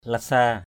/la-sa:/ (d.) mười vạn, một trăm ngàn. one hundred thousand.